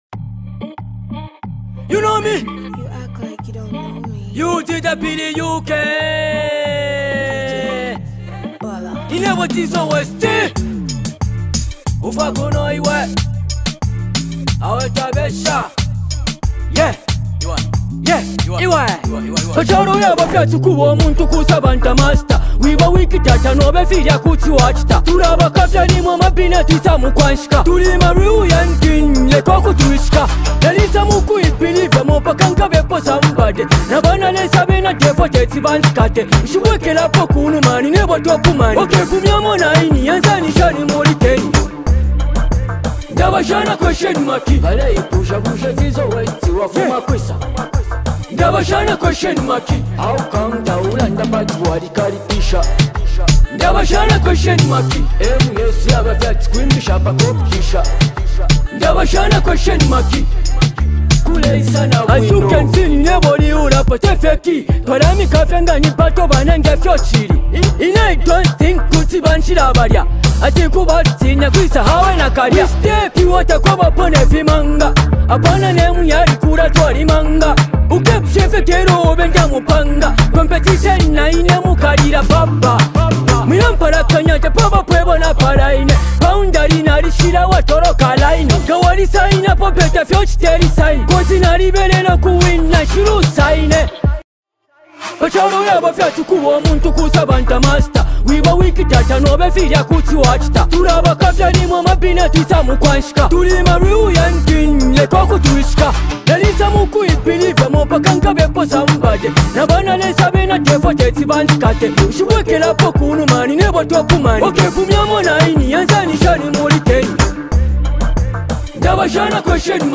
hip-hop
The melody is catchy and the production top-notch.